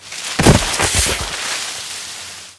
failjump.wav